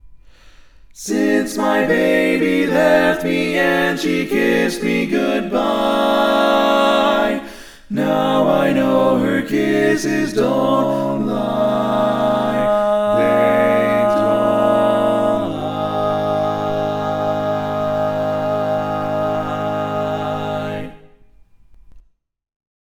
How many parts: 4
Type: Barbershop
All Parts mix:
Learning tracks sung by